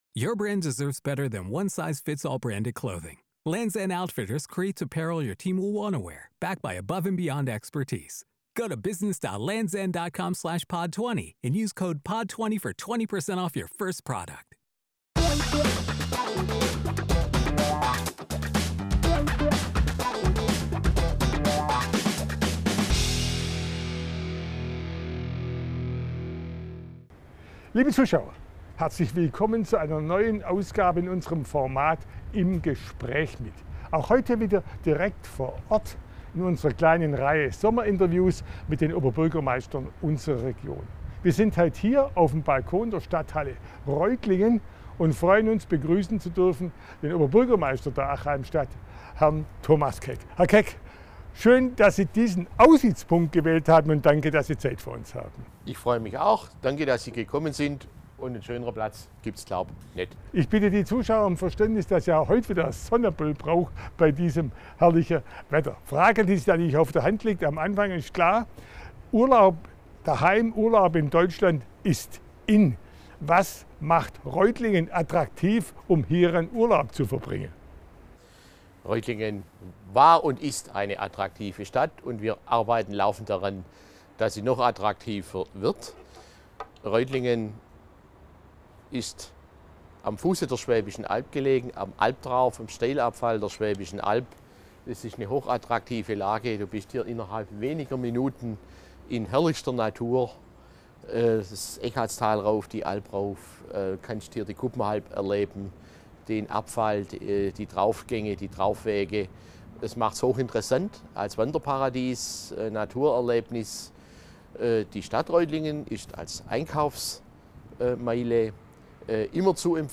Das Sommerinterview 2020 mit Thomas Keck, Oberbürgermeister von Reutlingen.